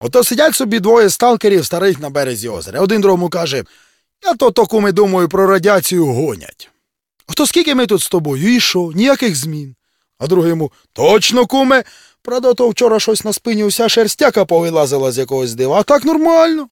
Копав файли Тіні Чорнобиля, відкопав озвучку, і курво: такі опрутненні жарти західняцьким діялектом, що пиздець.